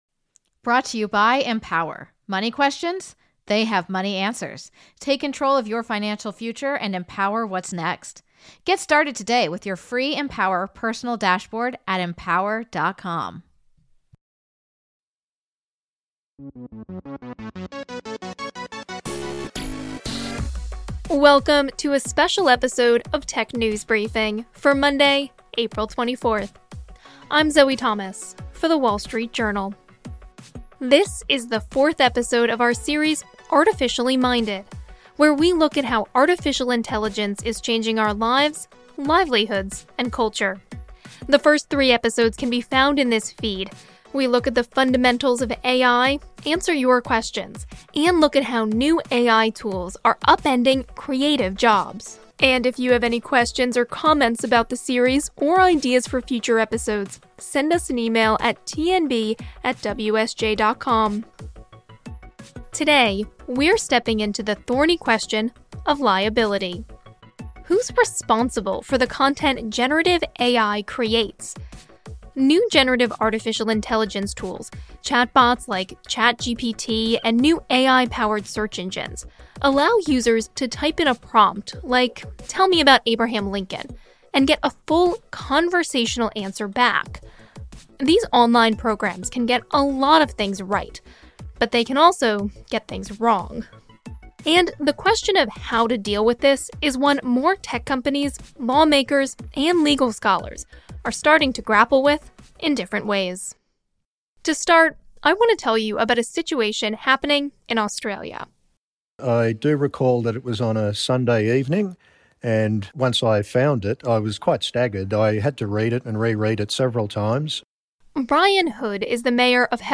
talks to a legal expert and an AI ethicist